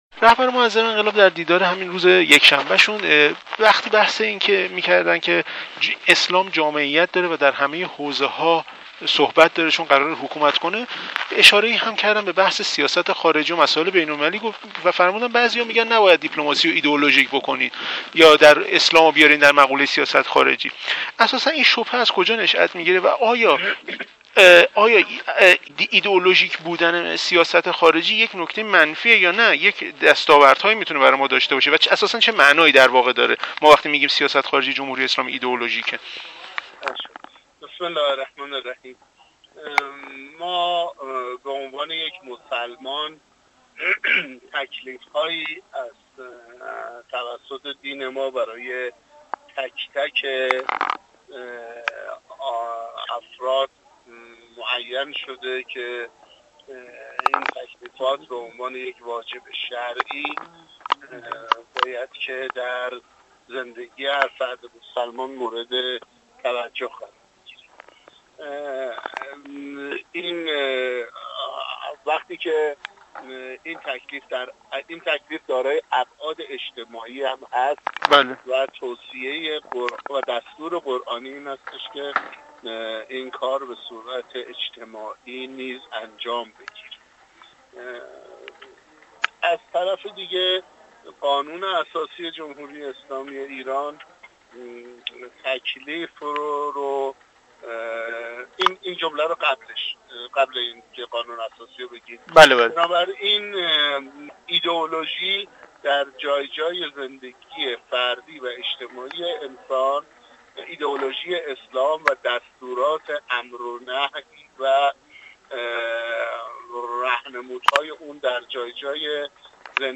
گفتوگو با مجتبی امانی